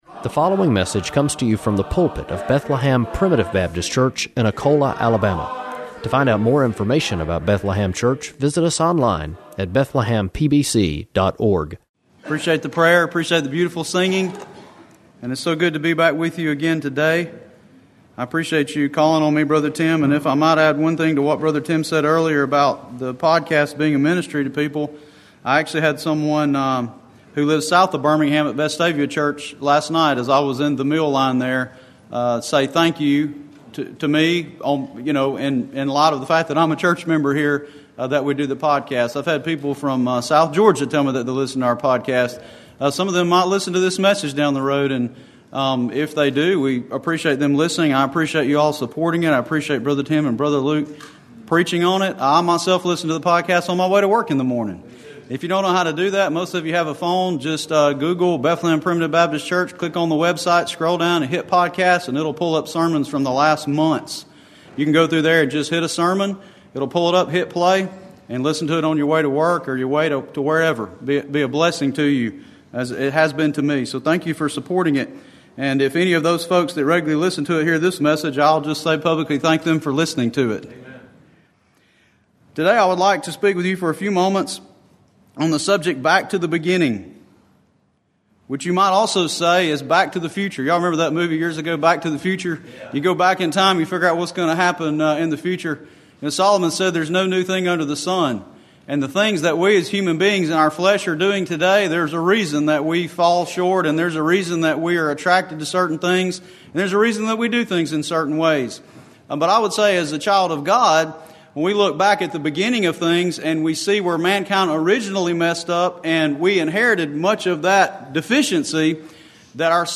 Preached November 19